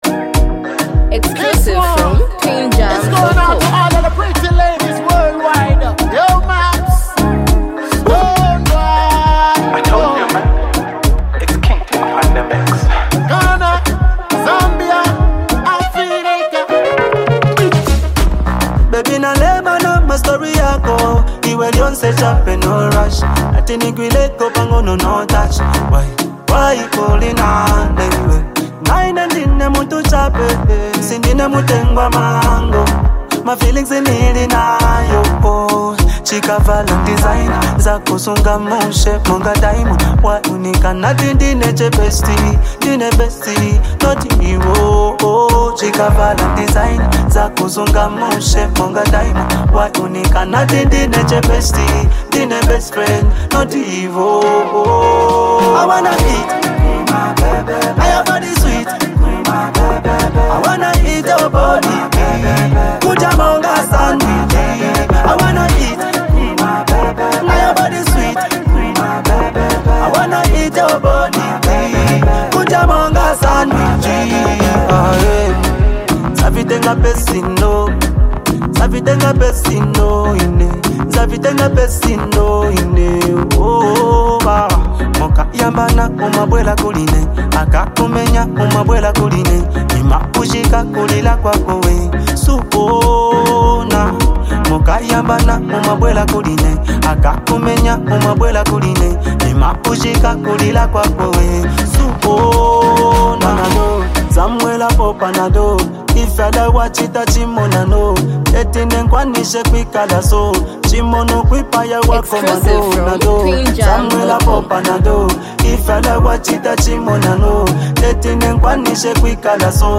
Ghanian Dancehall artist